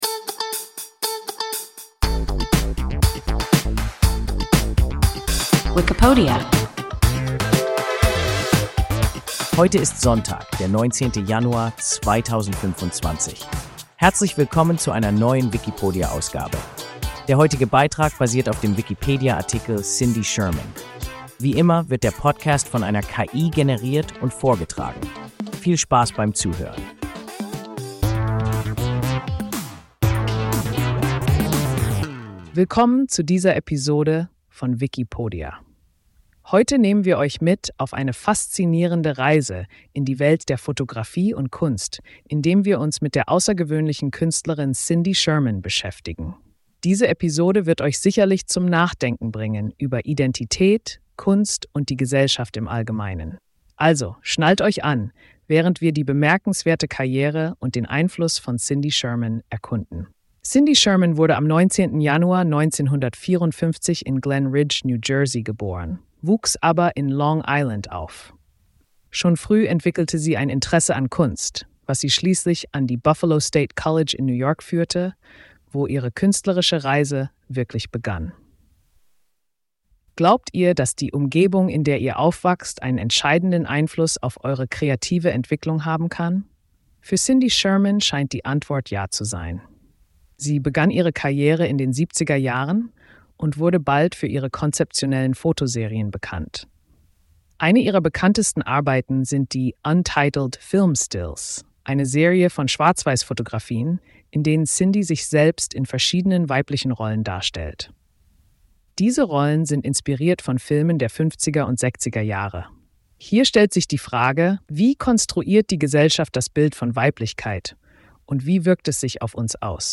Cindy Sherman – WIKIPODIA – ein KI Podcast